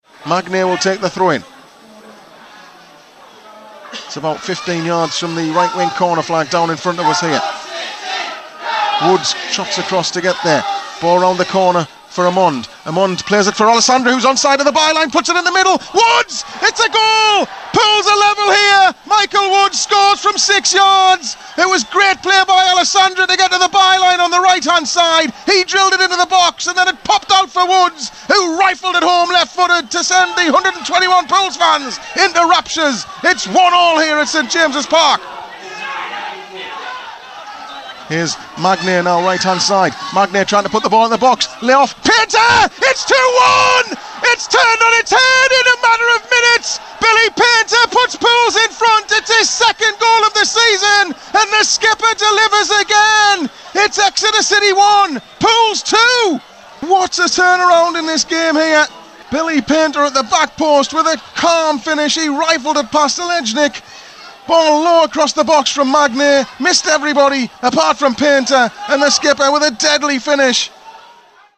Listen back to how the goals in Pools' win at Exeter City sounded as they went in live on Pools PlayerHD.